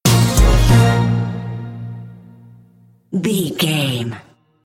Aeolian/Minor
B♭
drums
percussion
strings
conga
brass